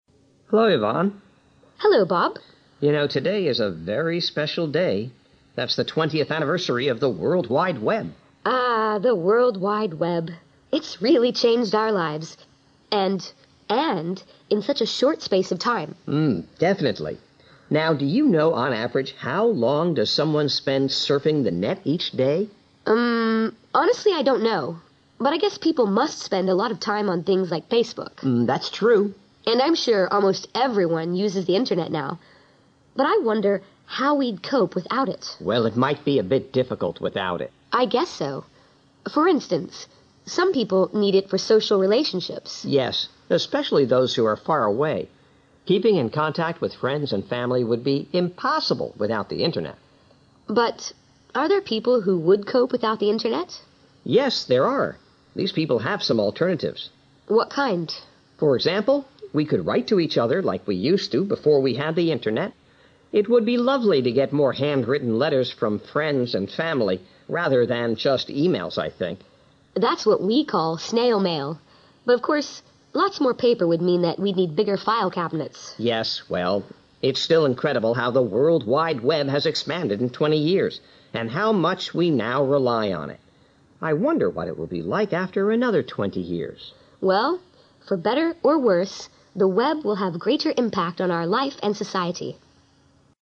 2012年英语专业四级:对话3